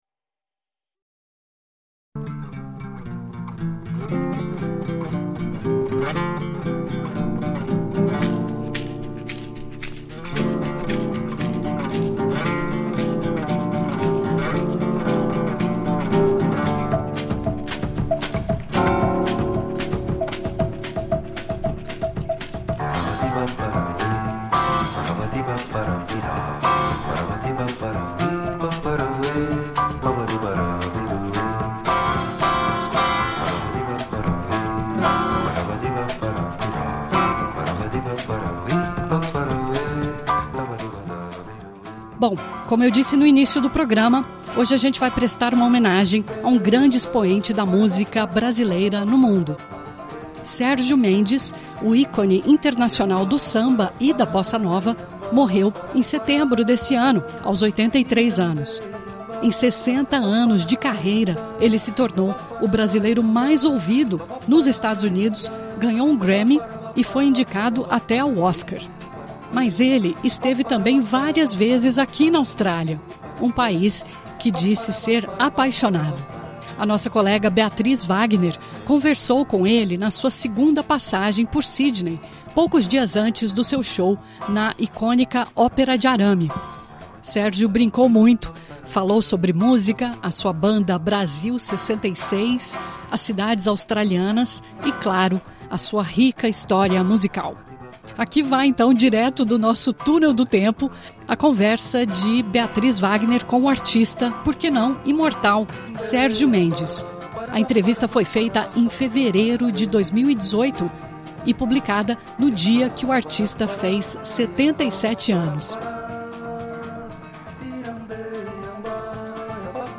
O grande expoente da música brasileira no mundo, Sergio Mendes, falou à SBS em Português em 2018 antes do show histórico na Sydney Opera House.